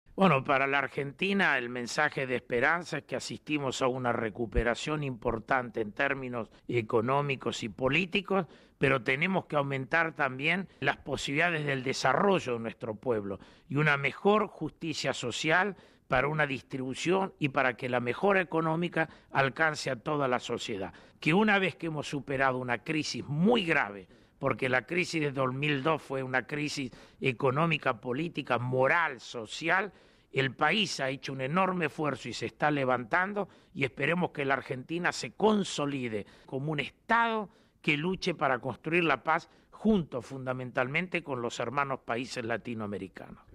Entrevista al embajador de Argentina sobre la exhortación del Papa a construir la paz en todo el mundo